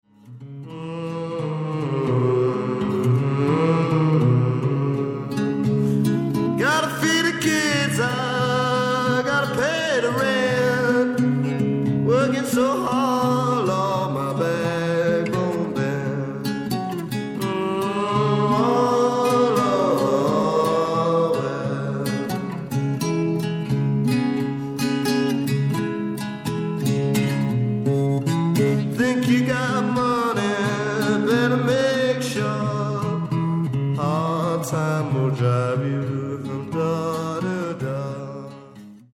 BLUES ROCK / COUNTRY BLUES